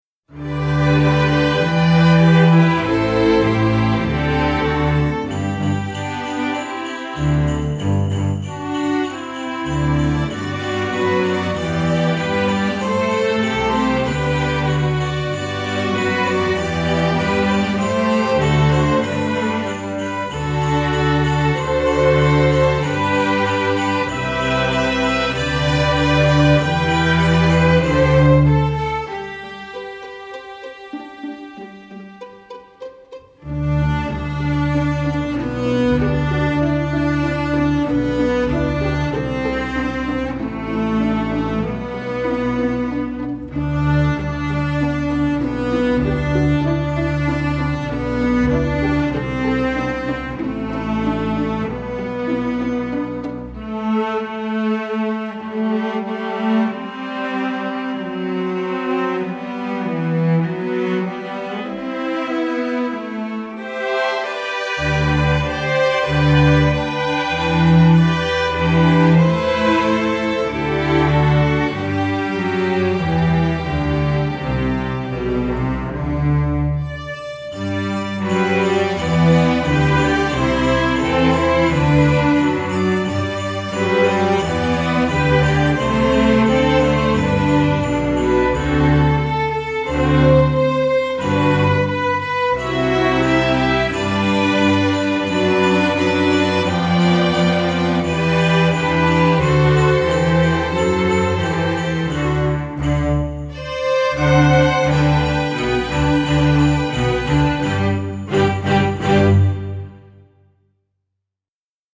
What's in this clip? masterwork arrangement, sacred